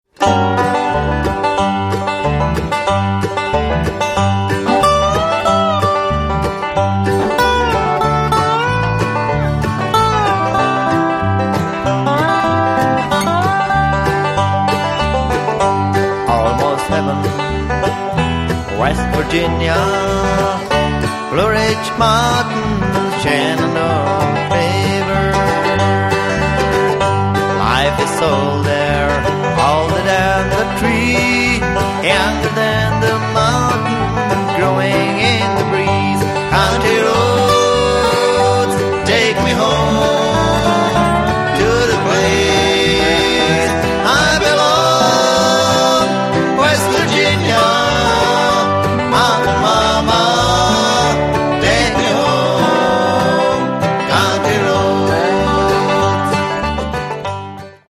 guitar, vocal
banjo, vocal
dobro, vocal
mandolin, vocal